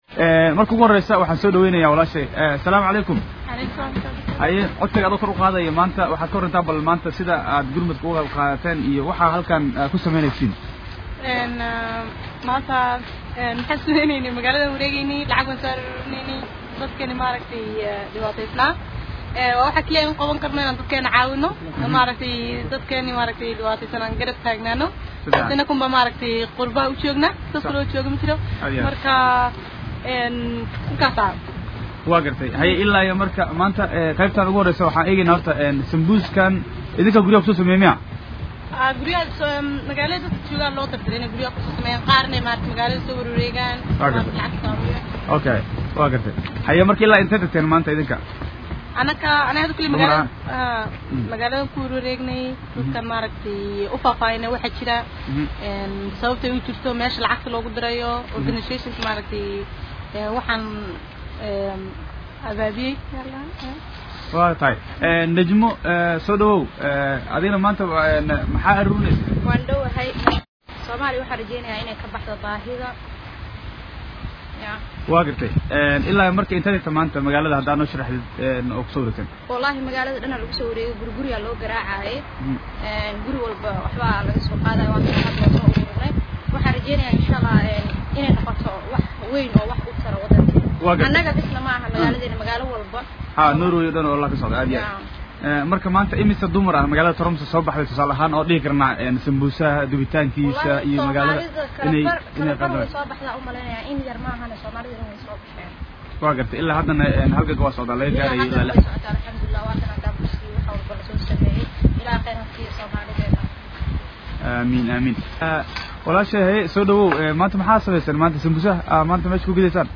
ayaa magaalada Toromso ee dalkaasi Norway ku wareystay qaar ka mid ah dadkii sameynayey Ololaha Gurmadka, gaar ahaan haweenka oo fagaaraha magaaladaasi ku iibinayey cuntooyin kala duwan